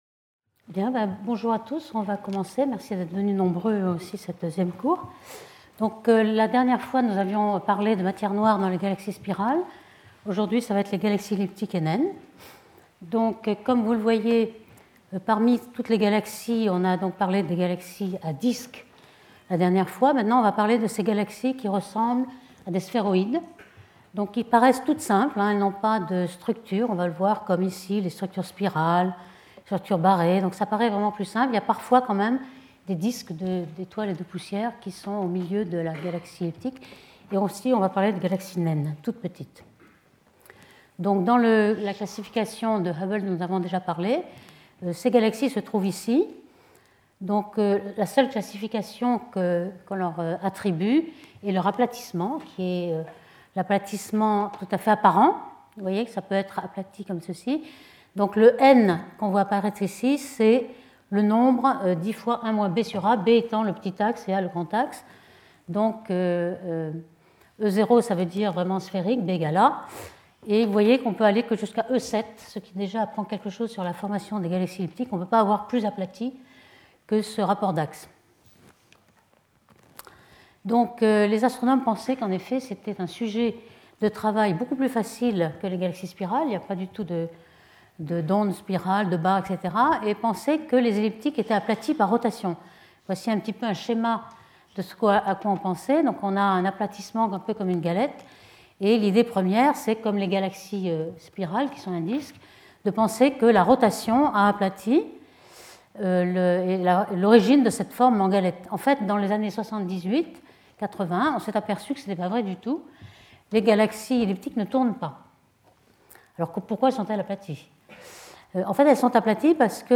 Cours